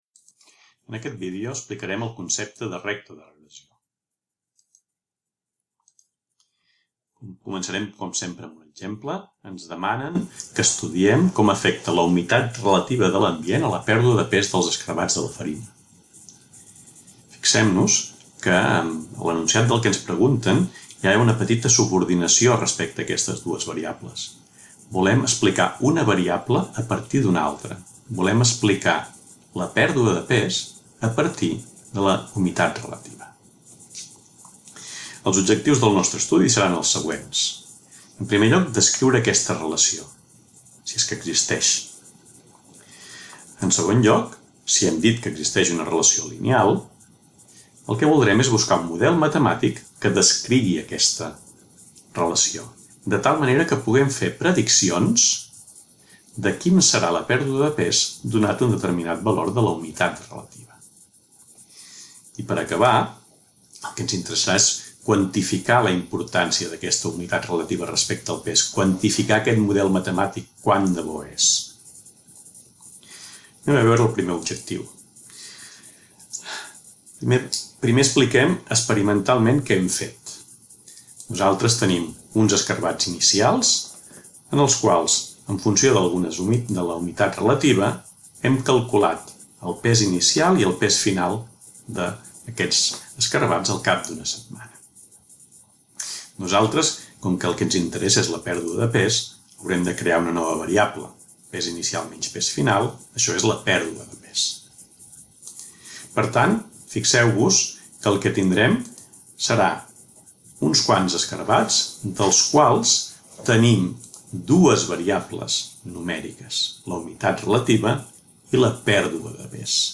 Lesson of the subject of Theory of statistics on the regression line